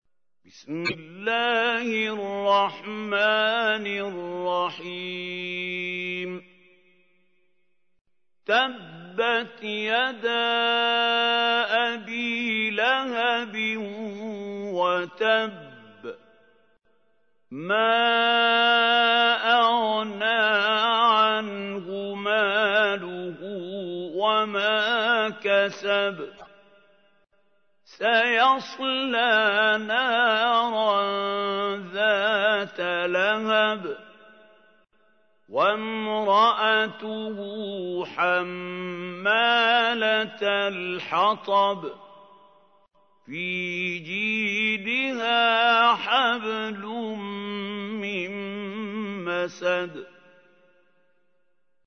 تحميل : 111. سورة المسد / القارئ محمود خليل الحصري / القرآن الكريم / موقع يا حسين